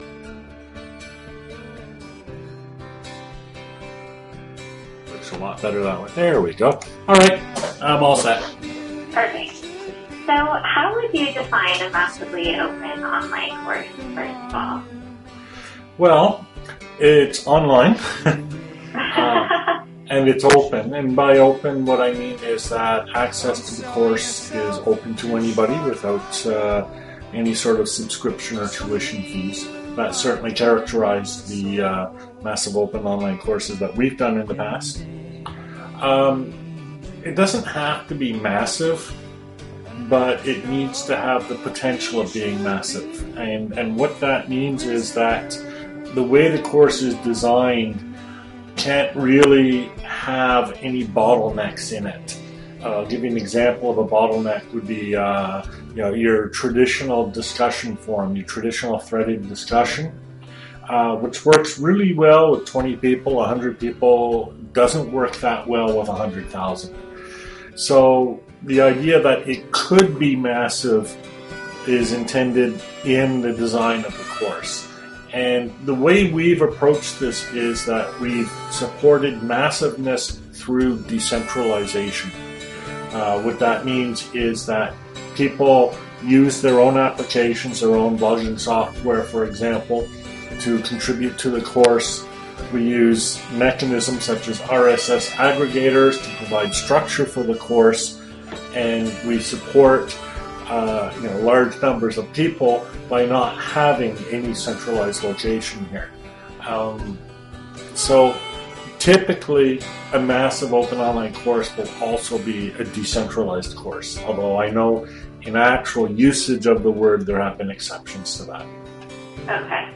Converge Interview
This is a discussion of our latest MOOC including some details about how the connectivist courses differ from other types of MOOCs. Also, we discuss just what is being 'opened' by open online courses. Sorry about the music in the background.